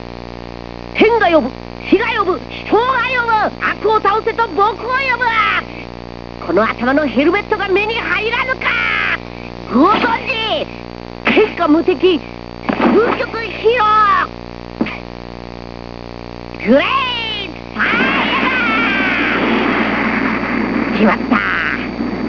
Well here are my (low quality) sounds.